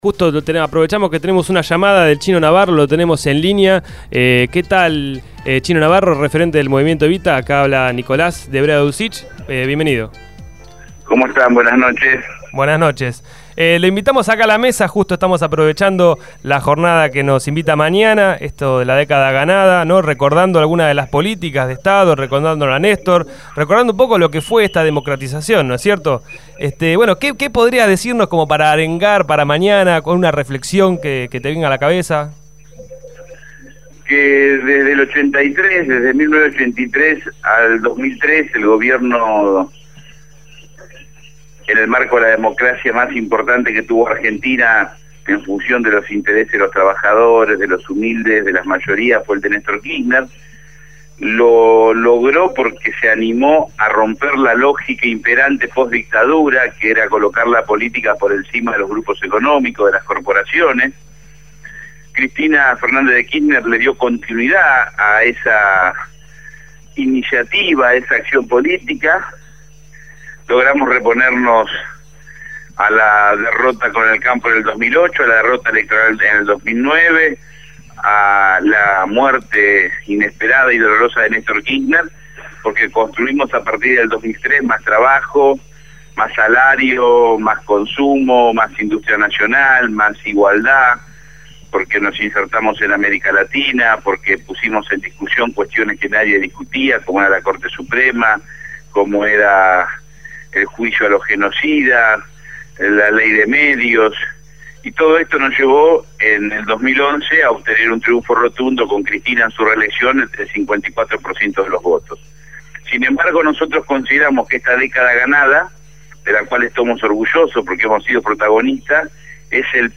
chinoFernando “Chino” Navarro, diputado provincial del Frente Para la Victoria e integrante de la Mesa Nacional del Movimiento Evita, habló con Sin Zonceras.